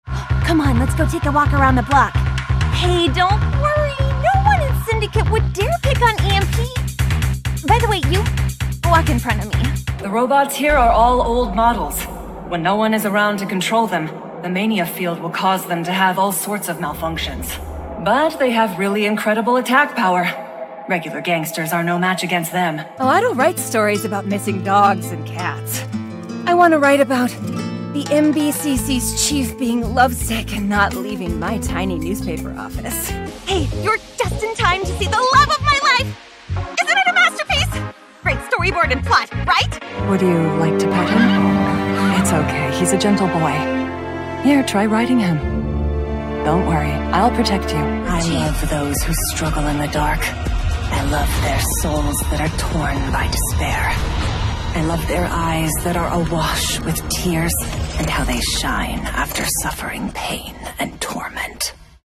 Voice demos
Versatile and flexible mezzo vocalist.